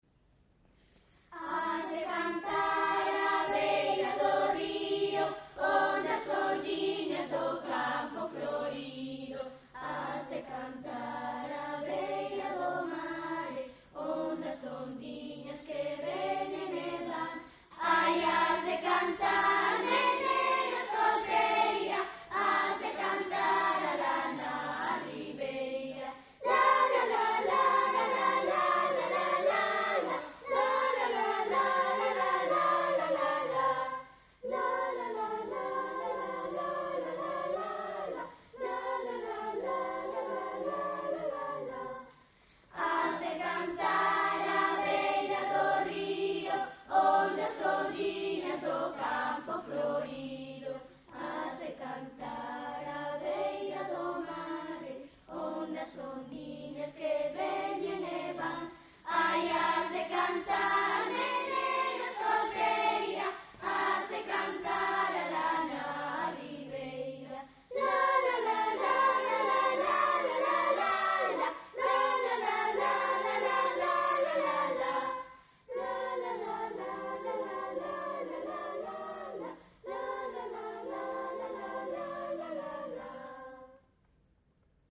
"Has de cantar" é un canto a dúas voces para ser interpretado con movemento, a modo de danza colectiva. A melodía principal está recollida no Cancioneiro de Galicia de Carlos Villalba Freire como muiñeira.
Con todo, neste arranxo preséntase con un tempo máis lento para facilitar a coreografía e o canto colectivo.